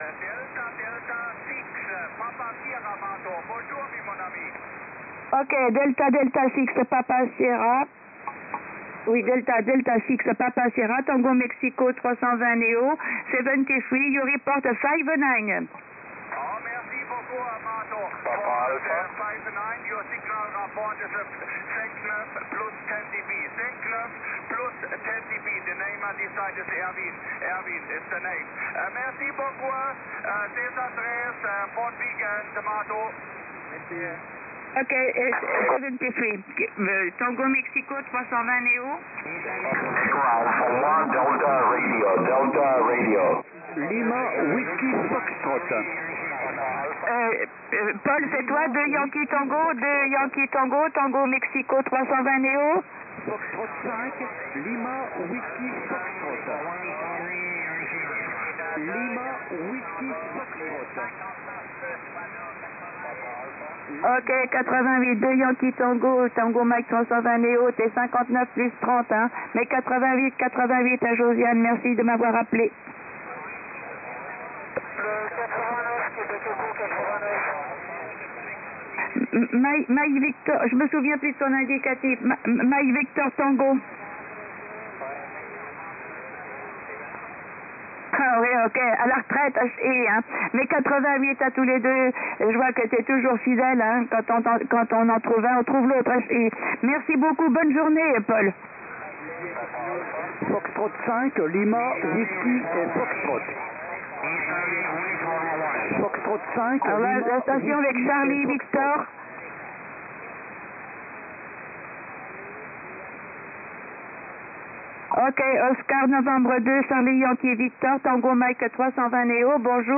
This is most valuable when a band is ‘open’ during favourable conditions and there are many powerful stations, and when the weak signal that you are chasing is very close to a hefty broadcast station. Here is a sample from an amateur band:
lsbpileup.m4a